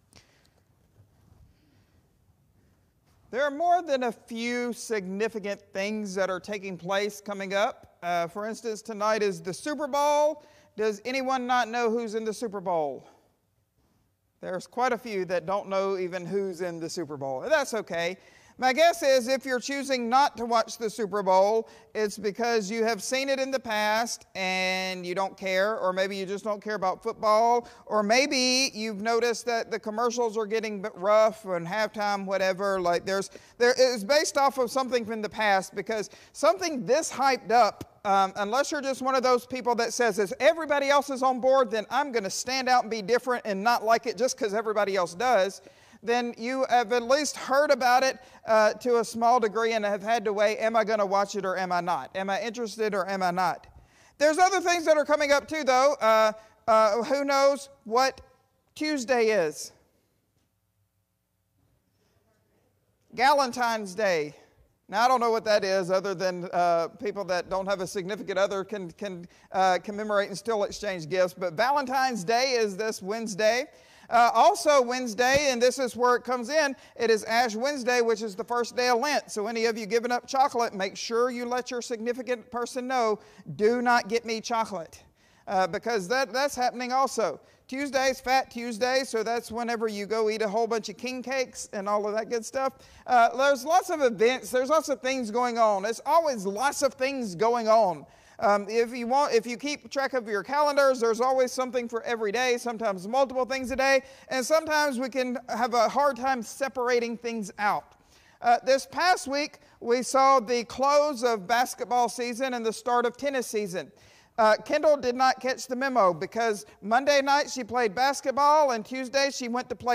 Sermons | Eastwood Baptist Church